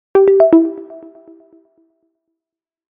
Scifi 8.mp3